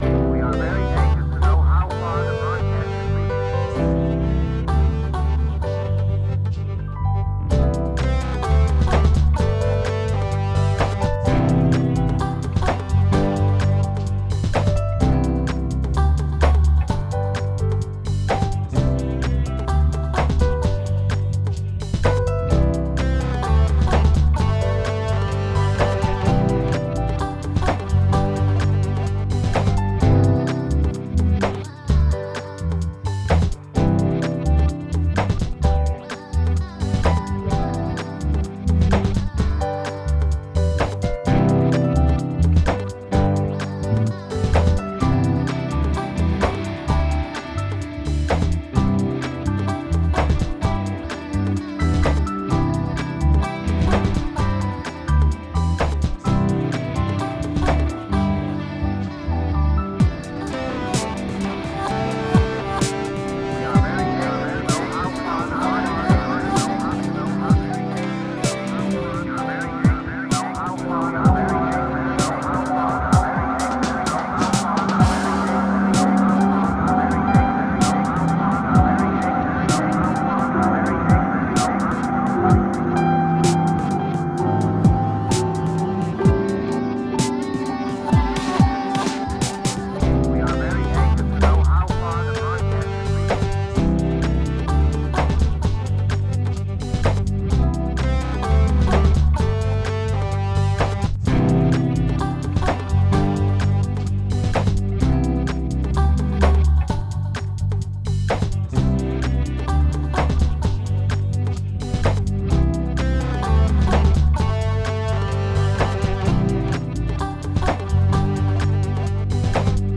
This started as some Loops in Loopy and suddenly everything I tried to accomplish was just possible.
Then some fun with Logics new session keyboard player added for fun.